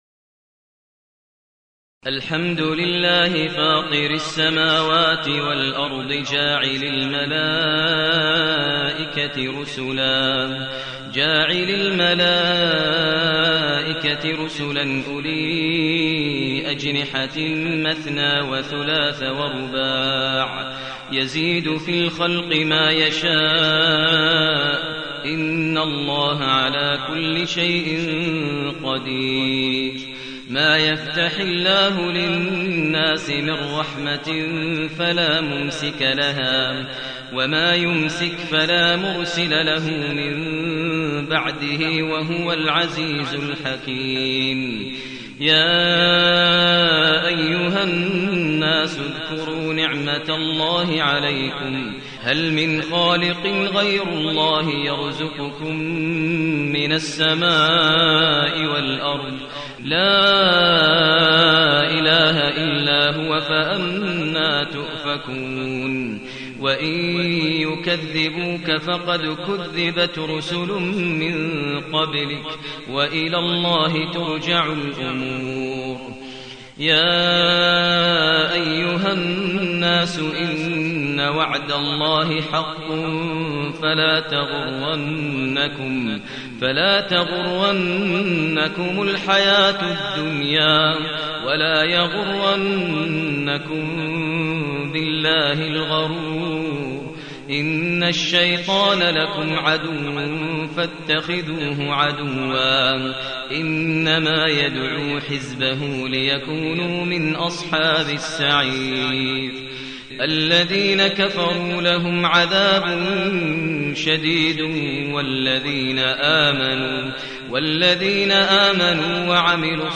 المكان: المسجد النبوي الشيخ: فضيلة الشيخ ماهر المعيقلي فضيلة الشيخ ماهر المعيقلي فاطر